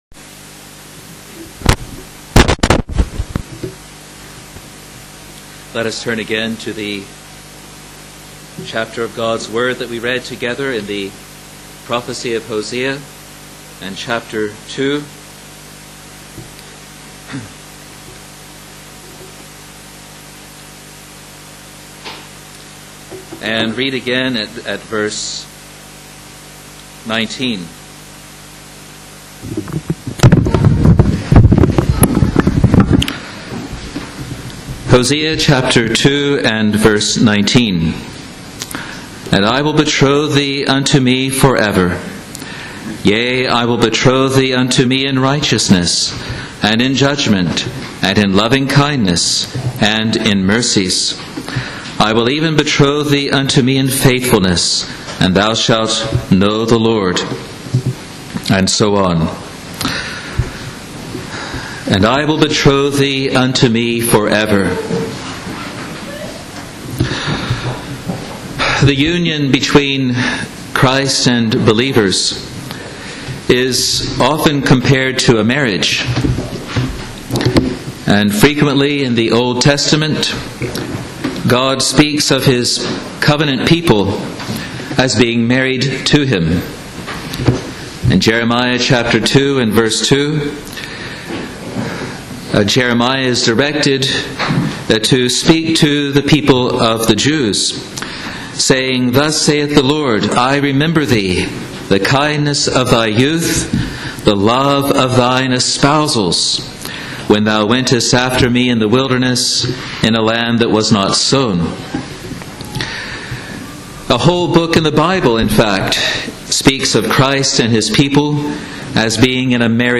Sermons | Free Presbyterian Church of Scotland in New Zealand